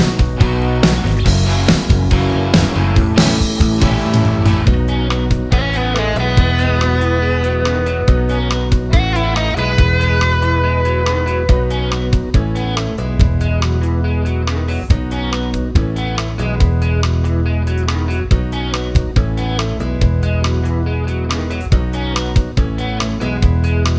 Minus Lead Guitar Soft Rock 3:45 Buy £1.50